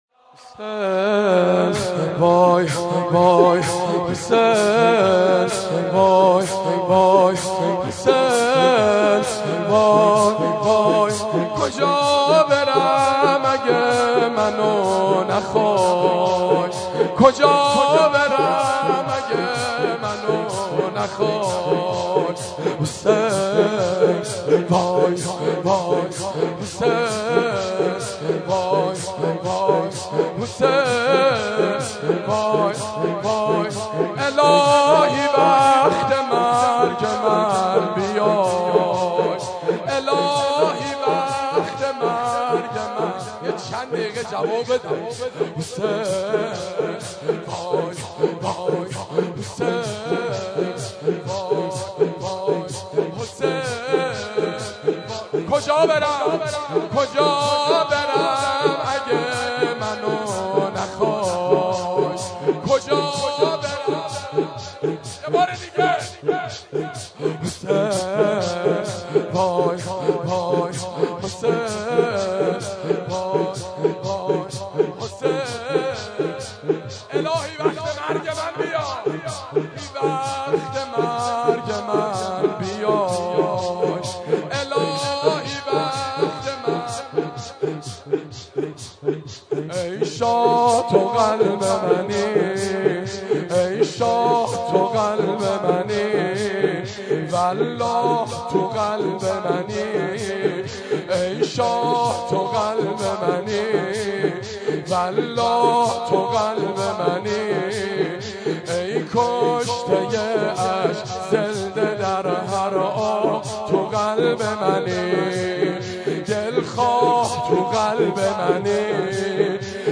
مناجات با خدا- شب 28 ماه رمضان 93 مهدیه امام حسن مجتبی (7).mp3